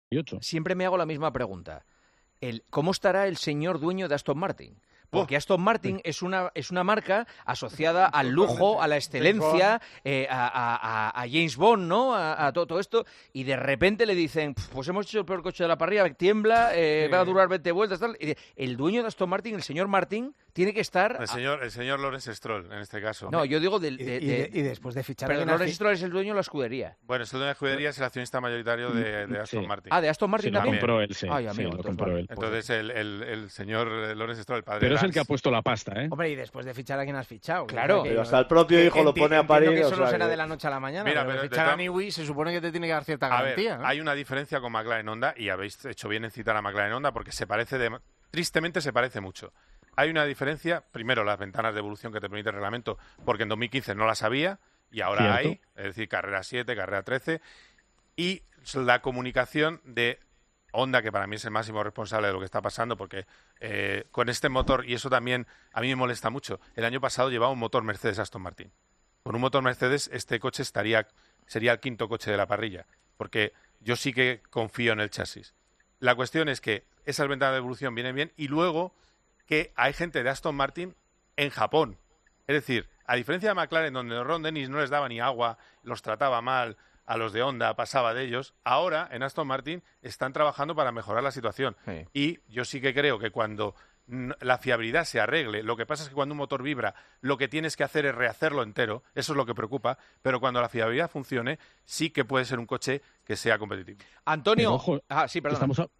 En el programa 'El Partidazo de COPE', el debate sobre la situación de Aston Martin ha puesto el foco en una cuestión lanzada por Juanma Castaño: qué pensará el dueño de una marca asociada al lujo, la excelencia e incluso a James Bond al ver que su equipo de Fórmula 1 tiene "el peor coche de la parrilla".